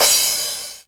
CRASH 909.wav